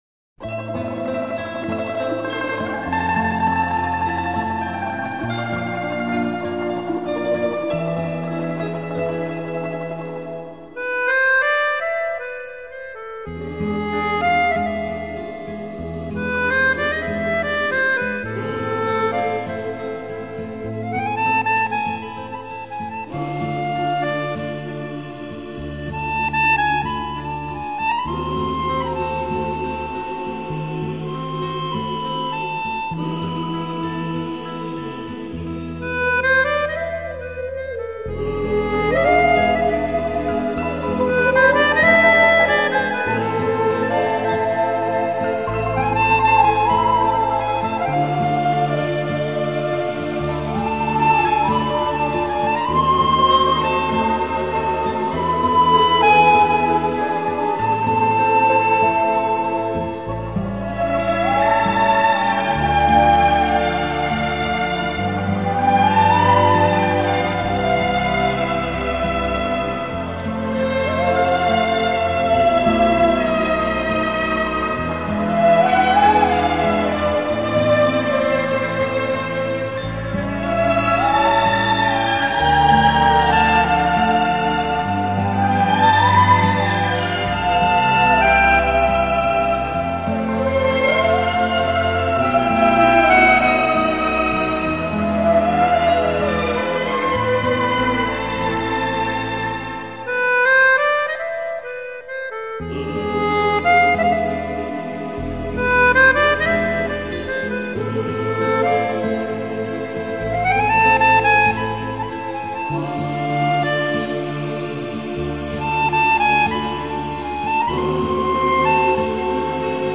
موسیقی بیکلام موسیقی متن